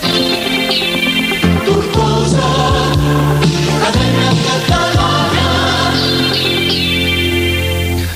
Indicatiu cantat de l'emissora
FM